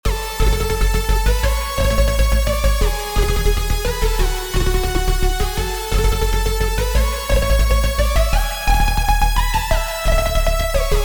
dnb melodic expansion
Astrovya Kit F#Min (Full)